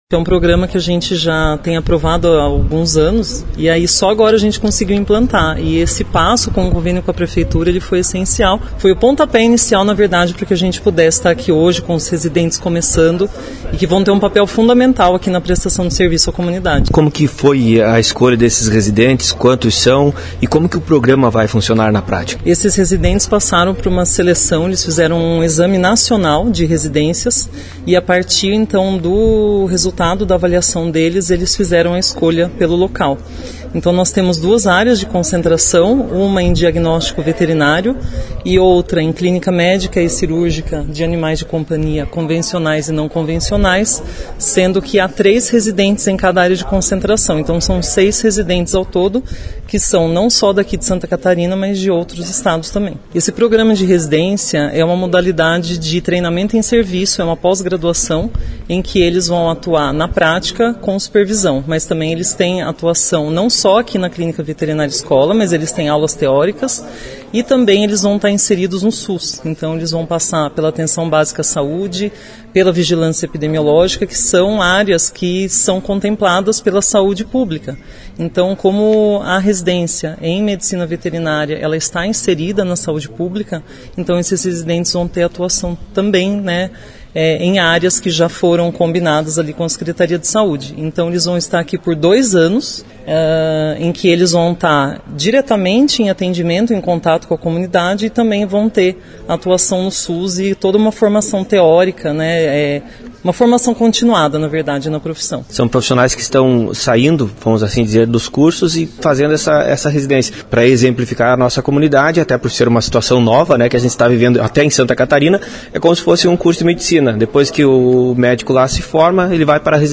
Ela também concedeu entrevista a nossa reportagem e explicou a importância deste momento histórico, uma vez que os benefícios vão muito além das salas de aula, eles serão sentidos no dia a dia da comunidade em geral e quem vai ganhar será a causa animal do município.